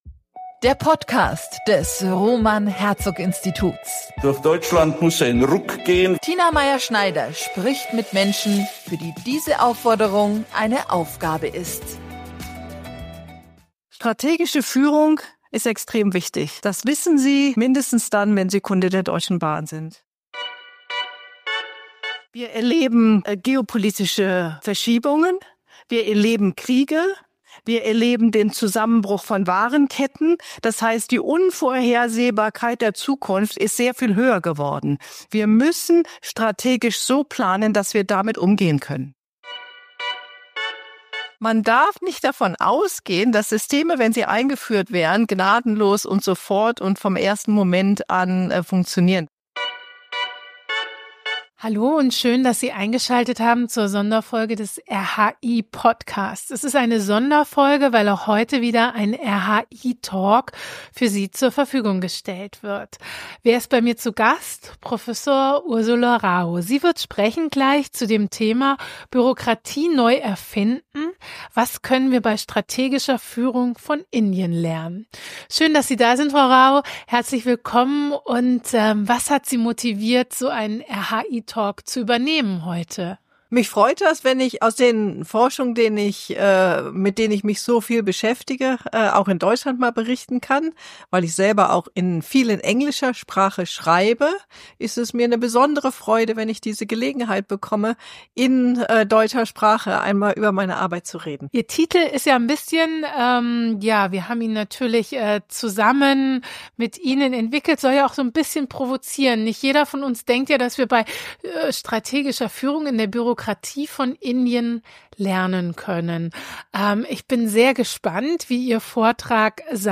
Was macht gute strategische Führung in Politik und Gesellschaft aus? Dieser Frage haben wir uns gemeinsam mit neun Expertinnen und Experten im Rahmen des RHI-Symposiums am 26.11.2024 gewidmet.